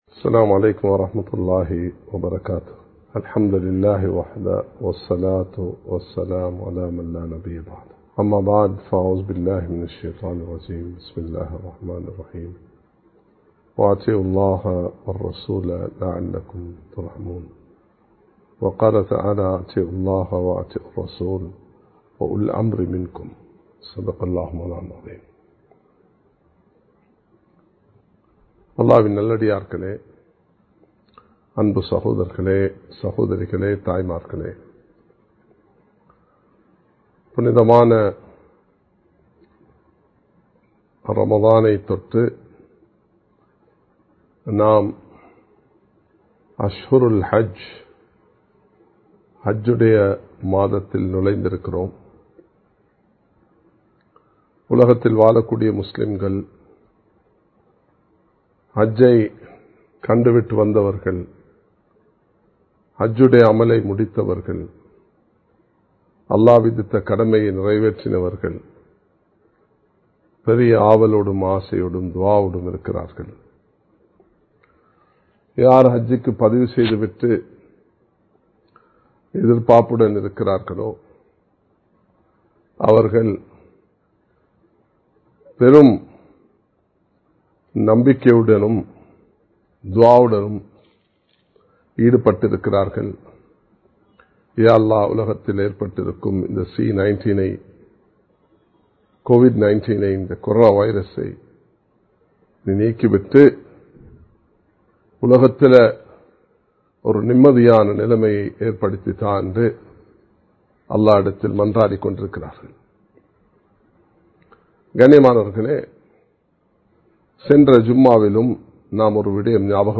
வழிப்படுதலின் அவசியம் | Audio Bayans | All Ceylon Muslim Youth Community | Addalaichenai
Live Stream